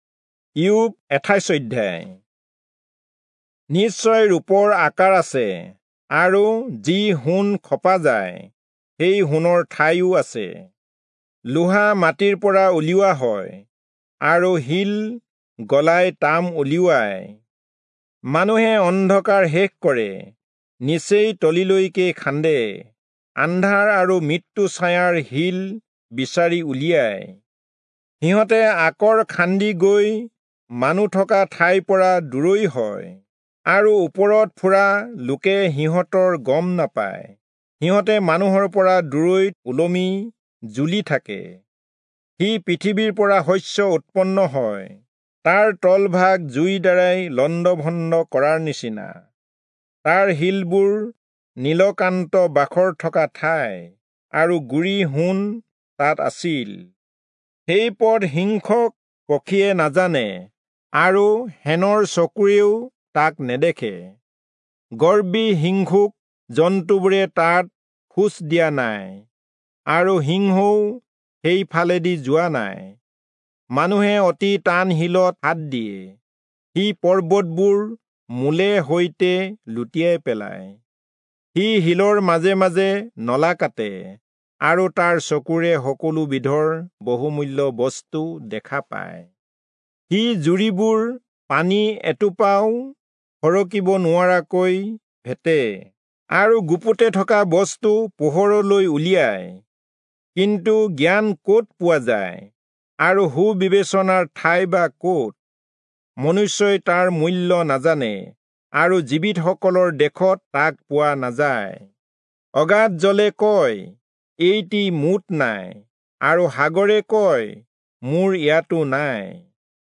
Assamese Audio Bible - Job 28 in Kjv bible version